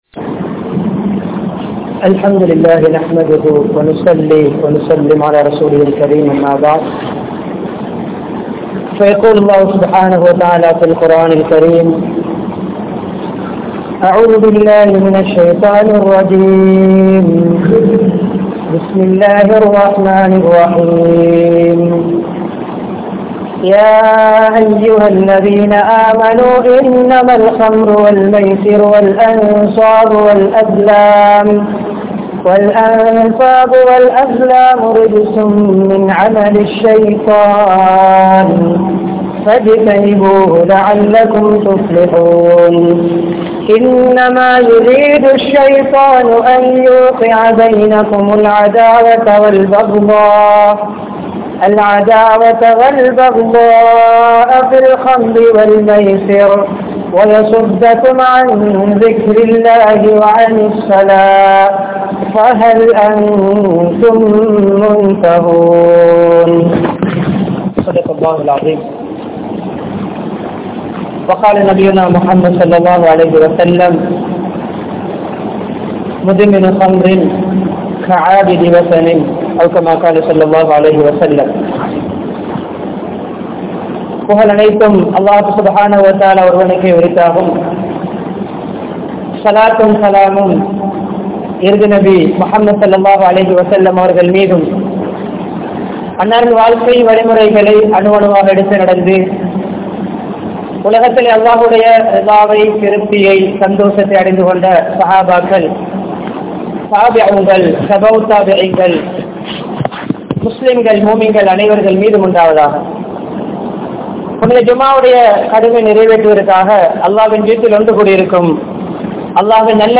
Boathai Vasthilirunthu Evvaaru Samoohaththai Paathuhaappathu? (போதைவஸ்திலிருந்து எவ்வாறு சமூகத்தை பாதுகாப்பது?) | Audio Bayans | All Ceylon Muslim Youth Community | Addalaichenai
Colombo 15, Kandauda Jumua Masjidh